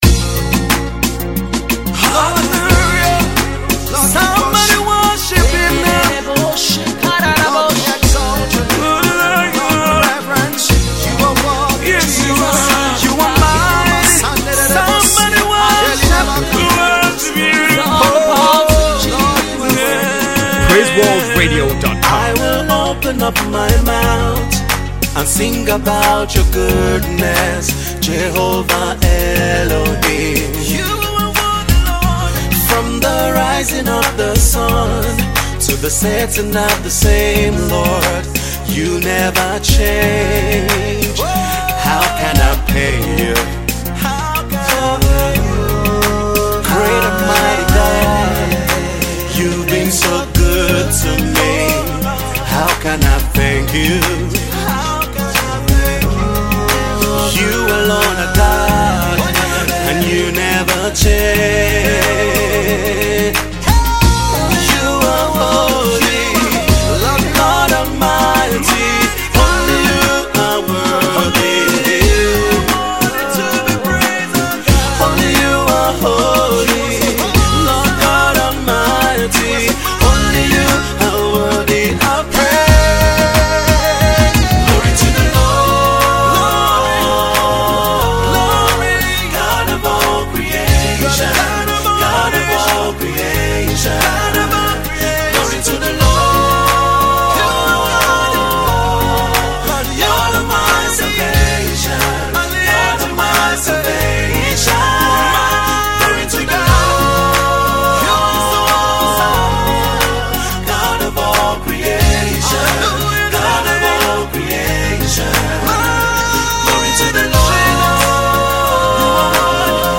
is a worship song